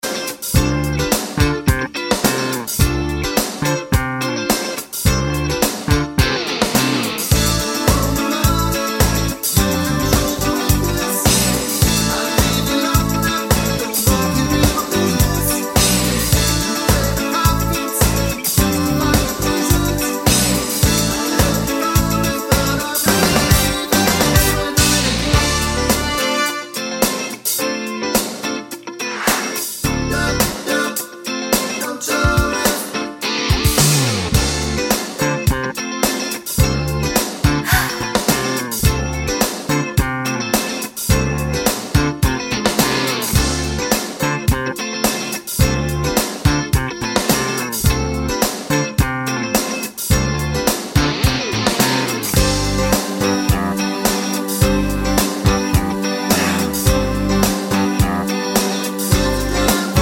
Without Verse Backing Vocals Pop (1980s) 4:00 Buy £1.50